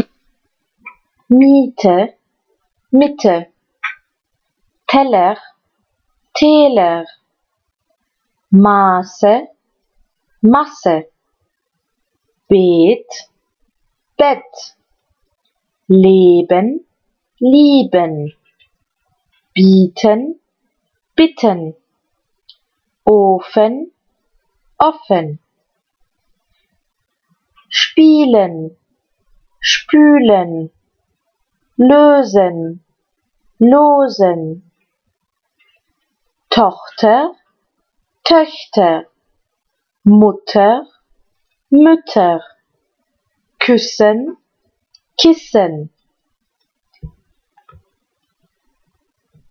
Faites attention aux sons longs et court etc...
Arrivez vous à écrire ces mots sous la dictée?
Le lien graphie-phonie